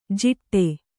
♪ jiṭṭe